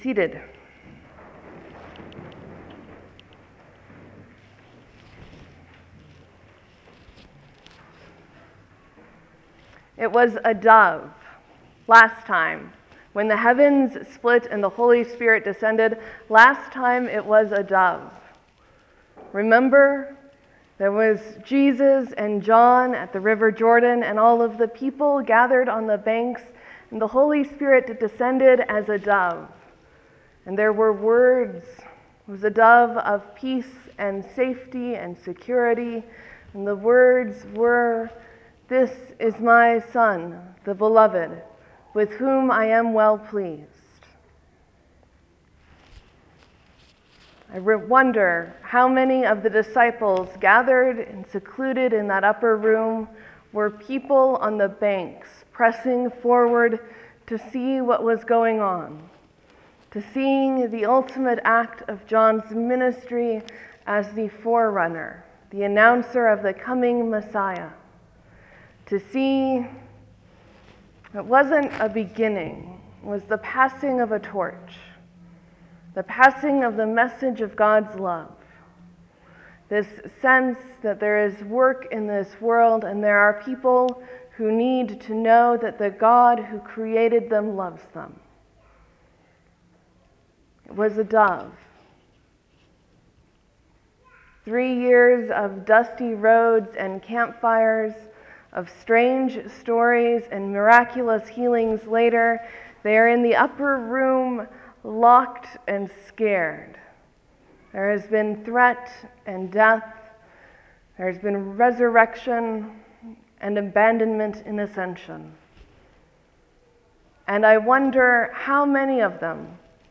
Pentecost, Sermon, , , , , , , 1 Comment